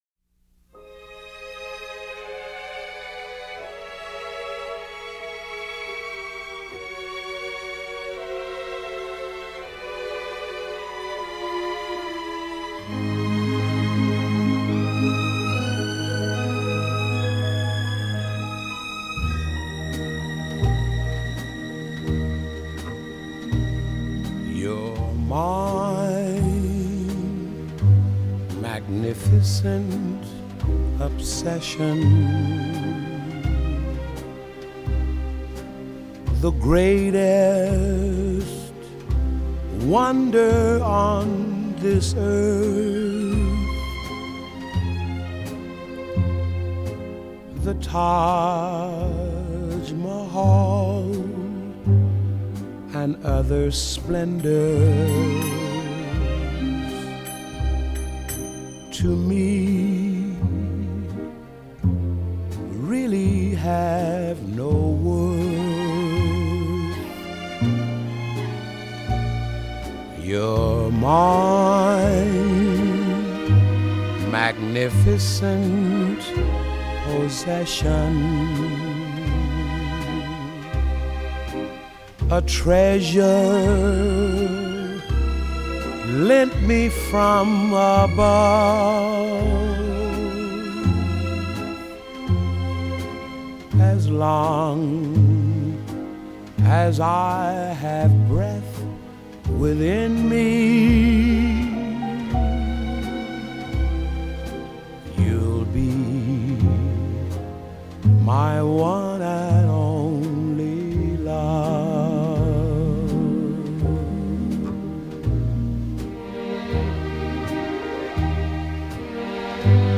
用深情無比的嗓音融化您的心！
採用原始類比母帶以最高音質之 45 轉 LP 復刻！